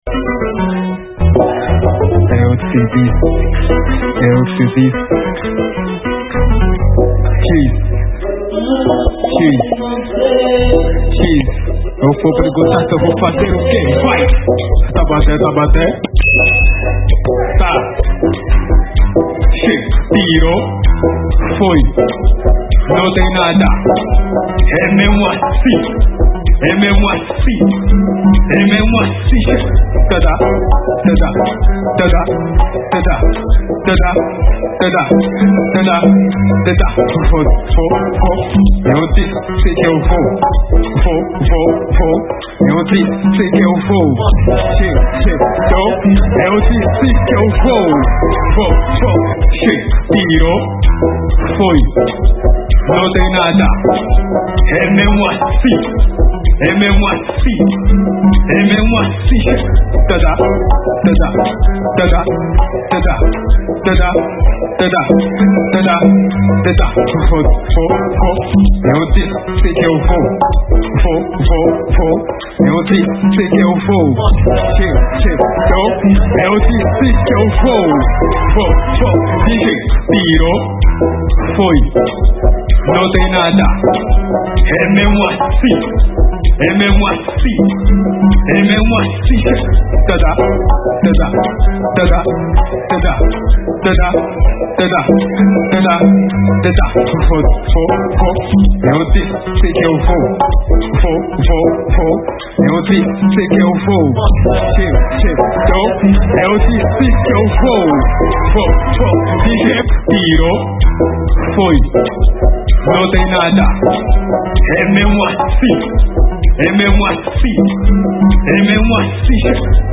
| Afro Dance